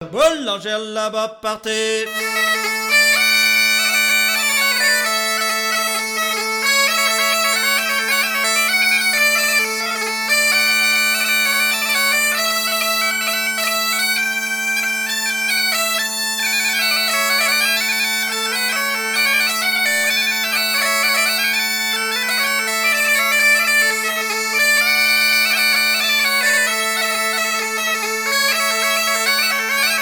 danse : quadrille : boulangère
Pièce musicale éditée